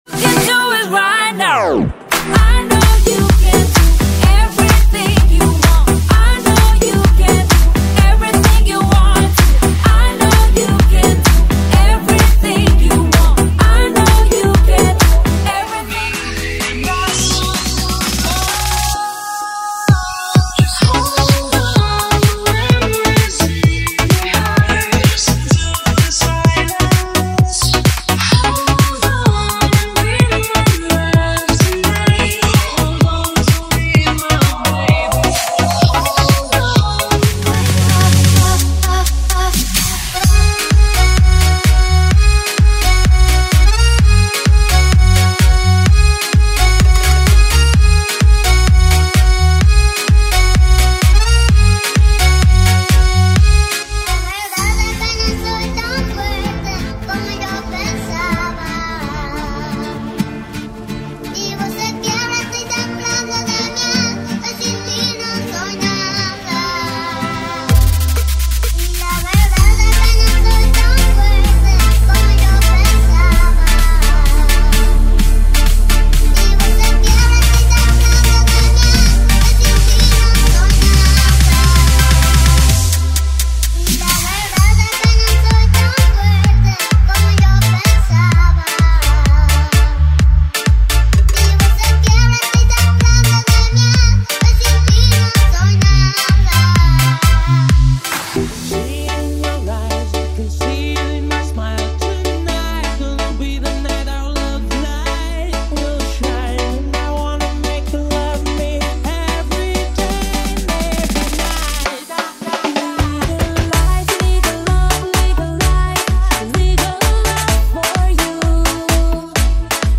Os Melhores Dance Comercial do momento estão aqui!!!
• Sem Vinhetas
• Em Alta Qualidade